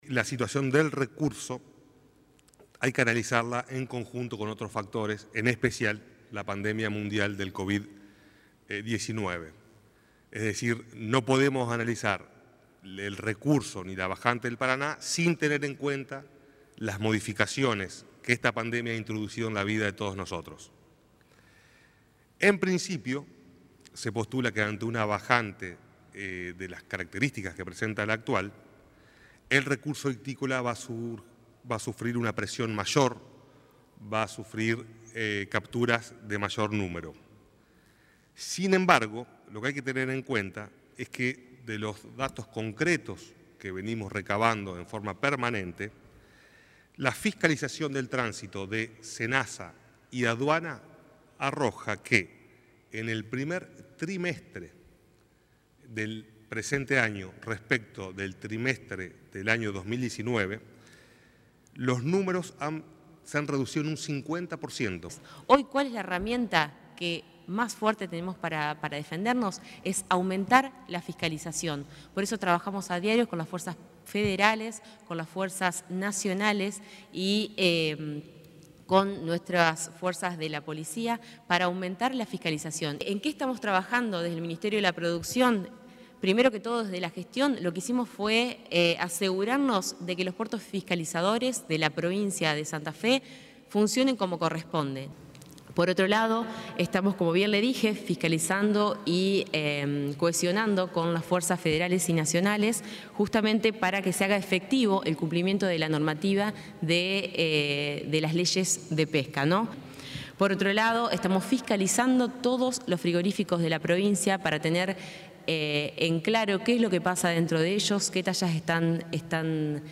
• Audio 1 de la conferencia de prensa